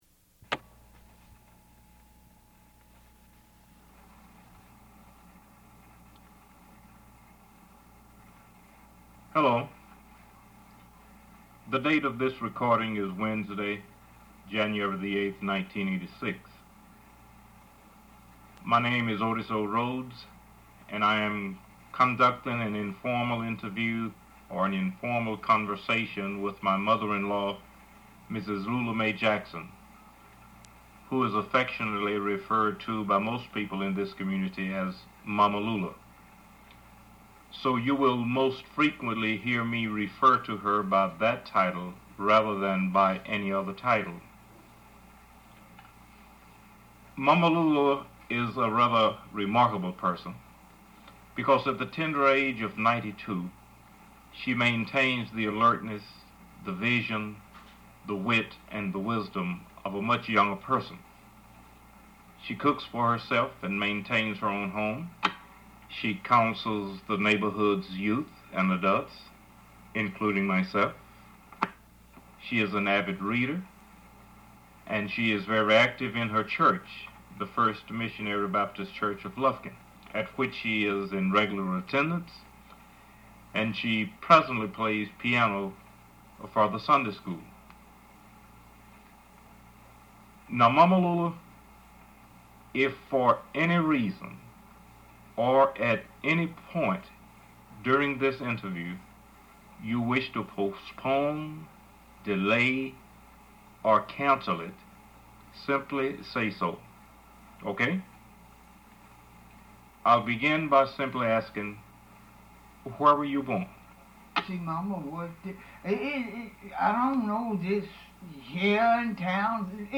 Interview 233a